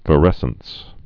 (və-rĕsəns, vī-)